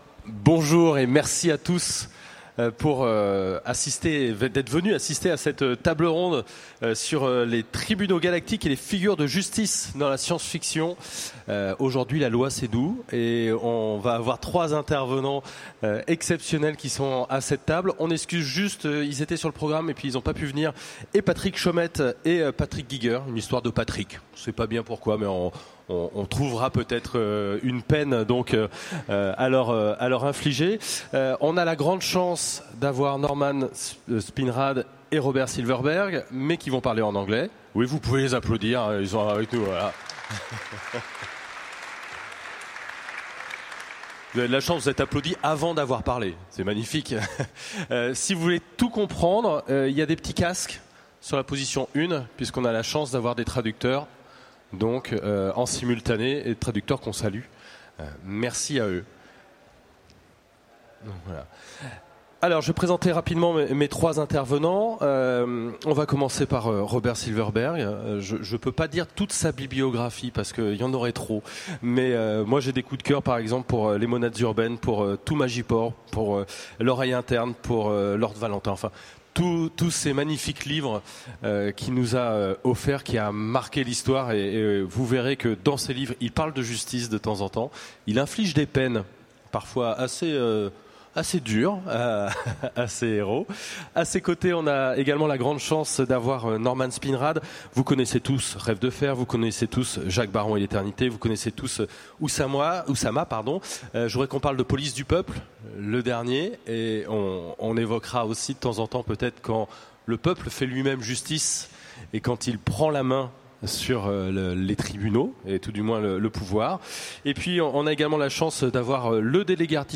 Utopiales 2015 : Conférence Tribunaux galactiques et figures de justice dans la science-fiction